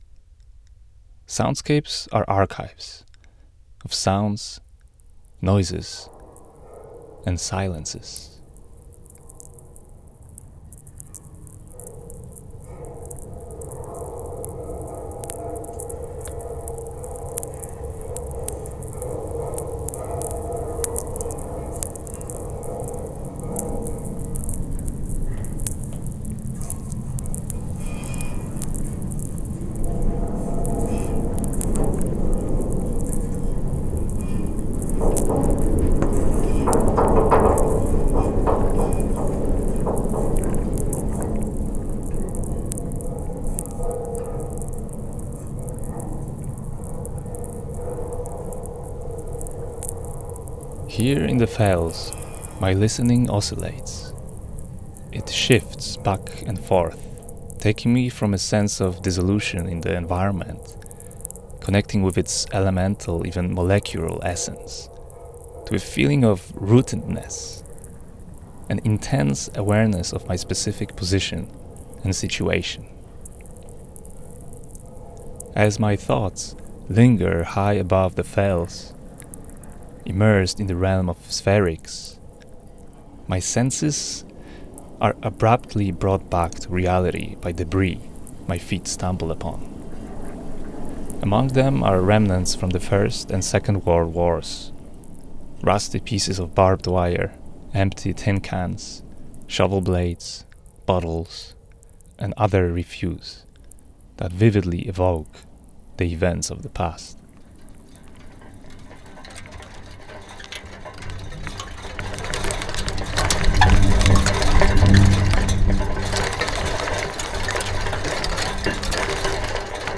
The Fells is a soundwalk composition that resulted from a research residency at the Kilpisjärvi Biological Station, located in the Finnish Arctic Circle, in August 2023.
All sounds featured in the composition originate from these local explorations.
The recordings that form the content of the piece span an array of biophonic, geophonic, and anthropophonic sounds. Biophonic recordings include those of reindeer and bird species typical of the region, such as golden plovers, red-throated loons, buzzards, and redpolls. Geophonic and atmospheric sounds include vibrations of soil exposed to wind, the rumbling of waterfalls, and natural atmospheric lightning discharges (so-called sferics). More importantly, the anthropophonic sounds featured in the piece capture the various ways in which wind animates elements of human infrastructure scattered across the fells.
Within this immersive experience, everyday city sounds become gateways to both perceivable and imperceptible soundscapes of the fells.